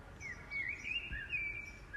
Vaak hoor ik alleen uren lang alleen dit achter elkaar precies hetzelfde